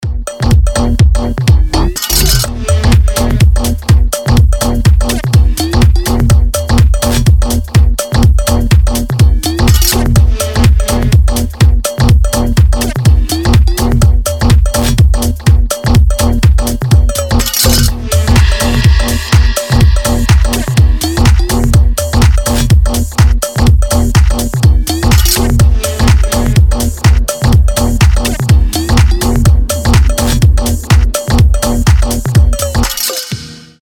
• Качество: 320, Stereo
dance
Electronic
EDM
без слов
Стиль: Bass house